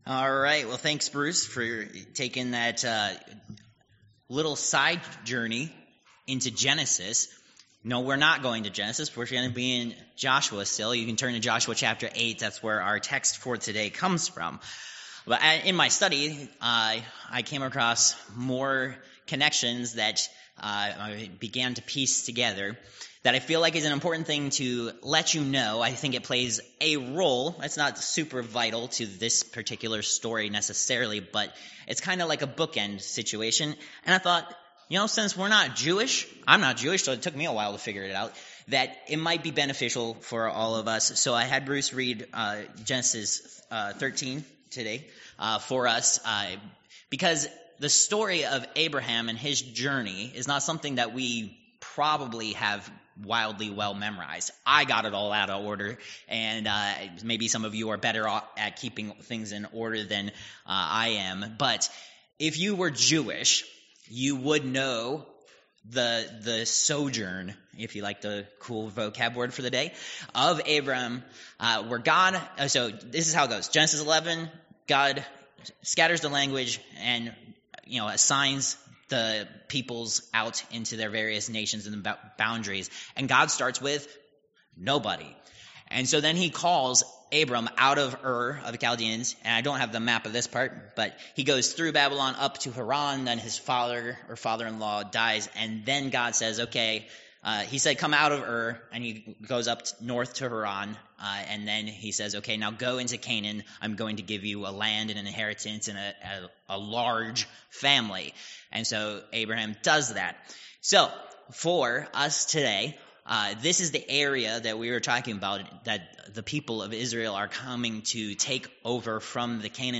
Josh. 8 Service Type: Sunday School Download Files Notes « Protected